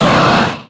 Grito de Latias.ogg
Grito_de_Latias.ogg.mp3